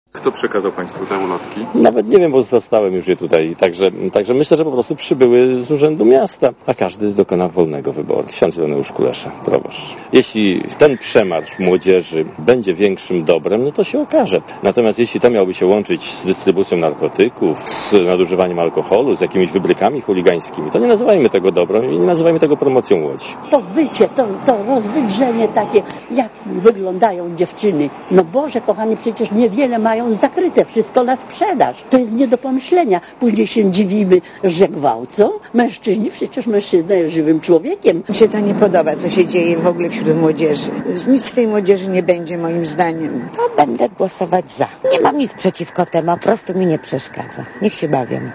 Komentarz audio (188Kb)